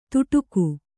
♪ tuṭuku